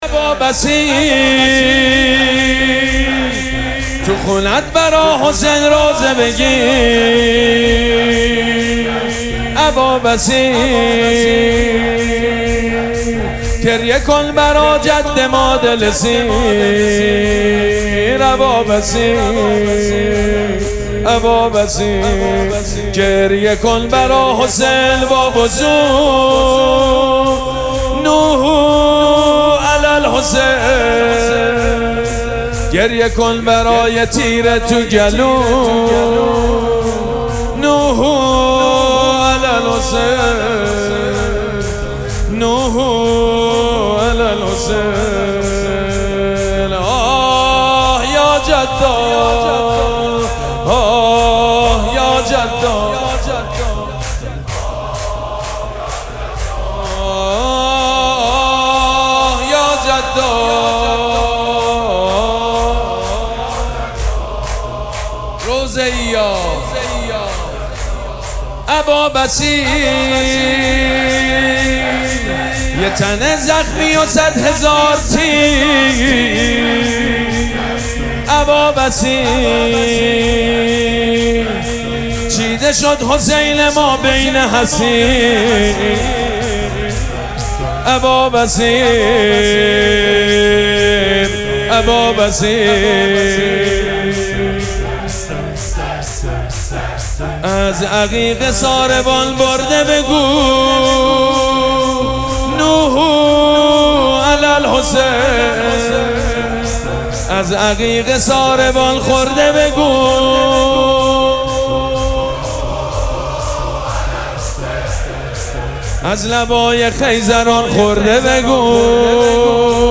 مراسم عزاداری شهادت امام صادق علیه‌السّلام
حسینیه ریحانه الحسین سلام الله علیها
شور
مداح
حاج سید مجید بنی فاطمه